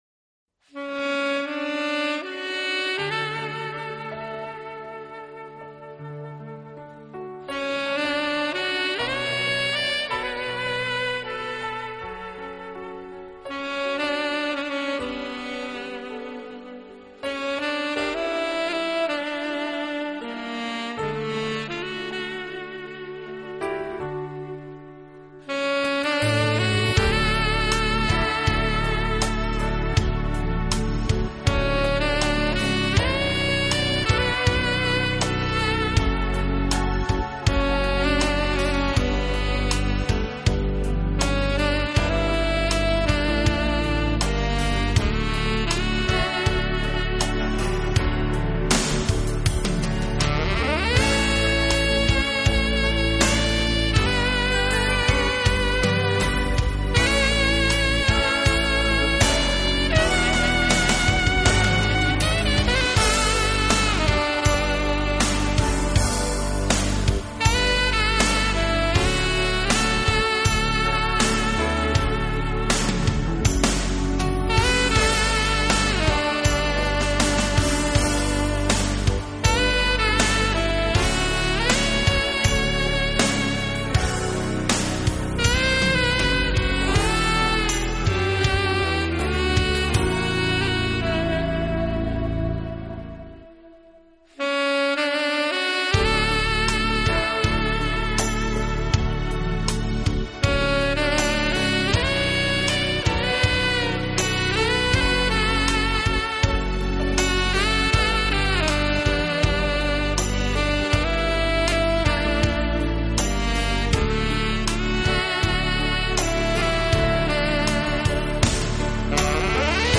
美国现代爵士及现代R&B乐坛的次中音萨克斯手。